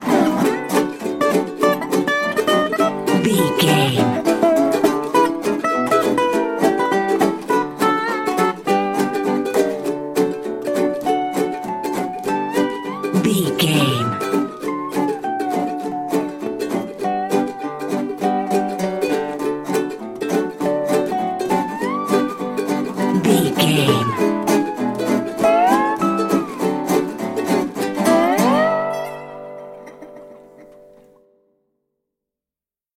Ionian/Major
electric guitar
acoustic guitar
drums
ukulele
slack key guitar